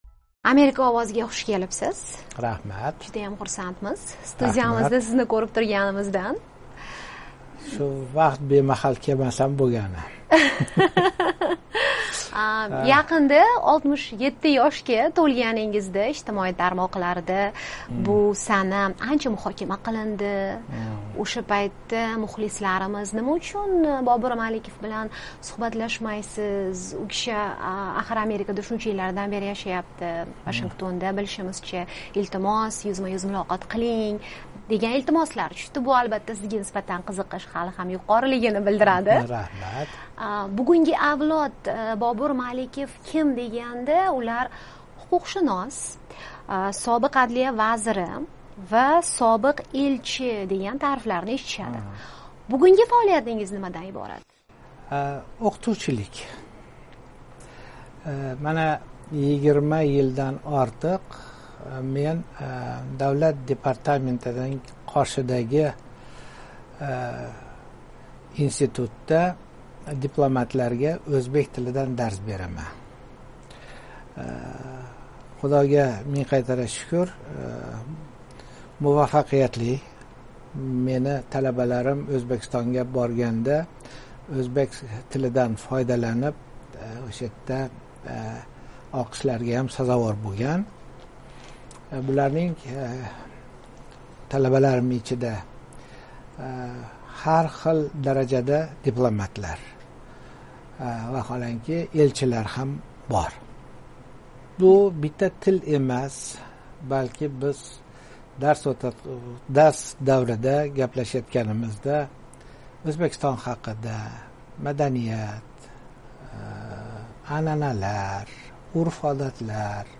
O'zbekistonning AQShdagi sobiq elchisi, hozirda Davlat departamenti qoshidagi diplomatiya institutida o'qituvchi Bobur Malikov bilan intervyu.